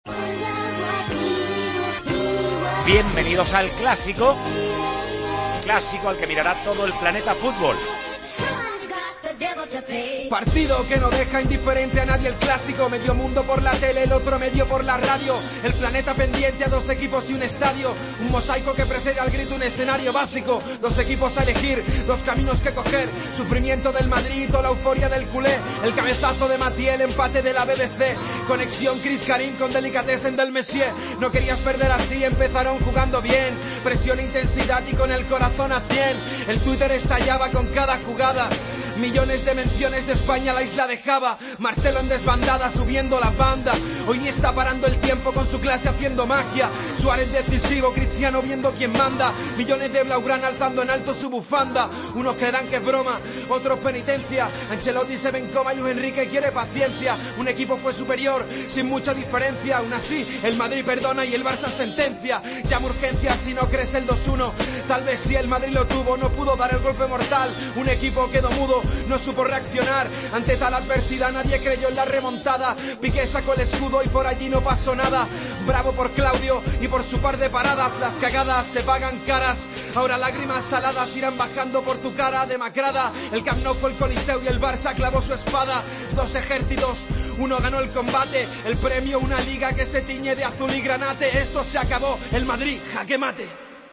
Tiempo de Juego a ritmo de rap